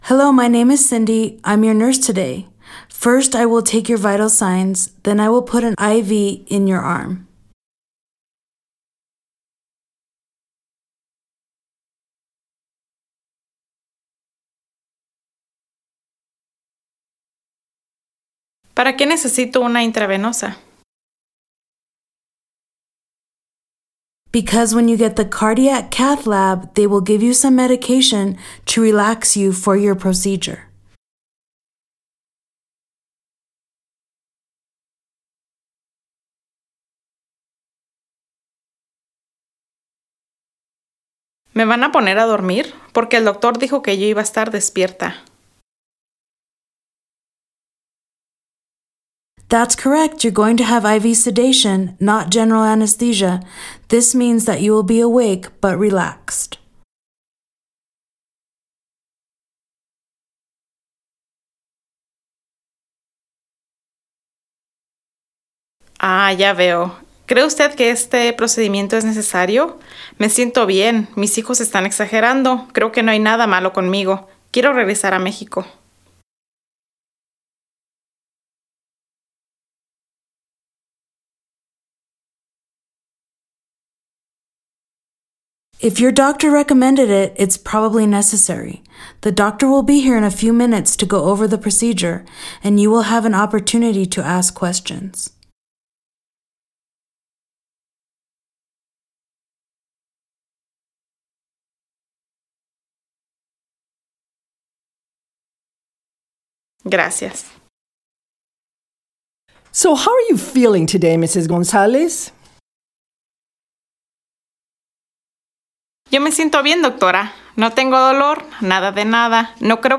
VCI-Practice-Dialogue-10-Cardiac-Catheterization-EN-SP.mp3